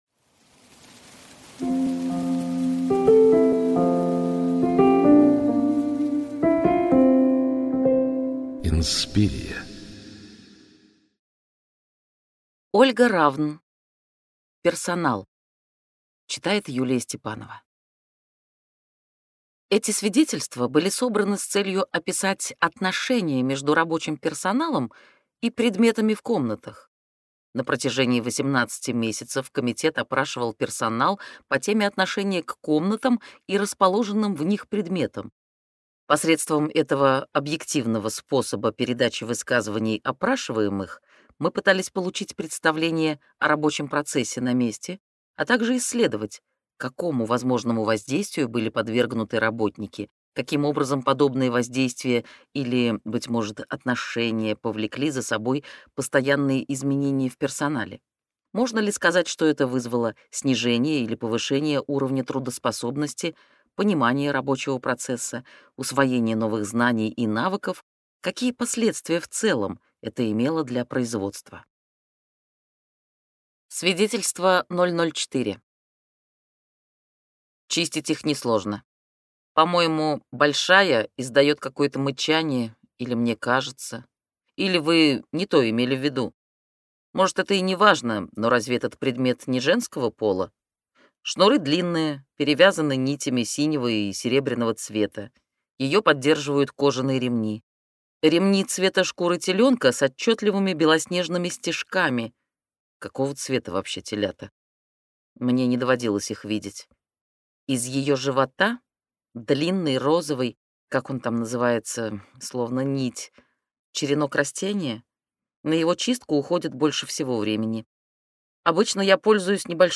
Аудиокнига Персонал | Библиотека аудиокниг